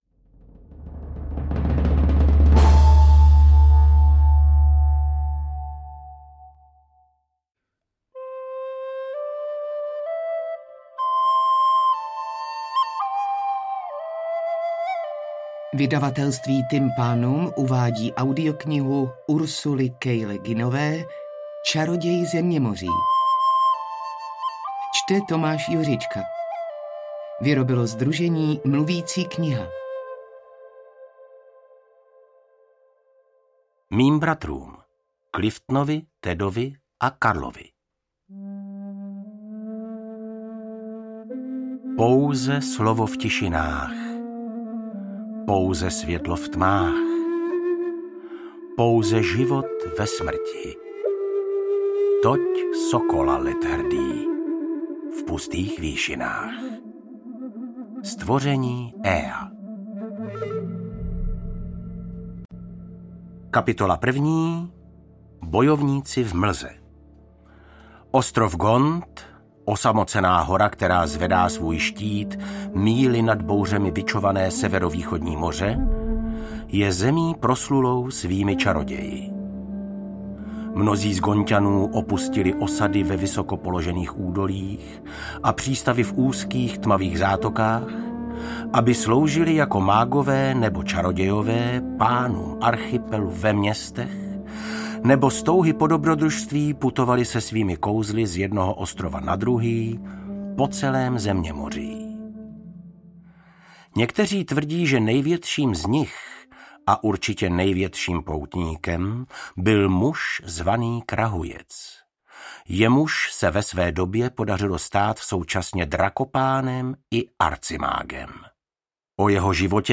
AudioKniha ke stažení, 45 x mp3, délka 7 hod. 33 min., velikost 414,9 MB, česky